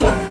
ServoMidD.wav